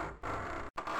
mech_creak.ogg